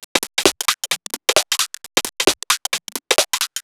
Index of /musicradar/uk-garage-samples/132bpm Lines n Loops/Beats
GA_BeatDCrush132-05.wav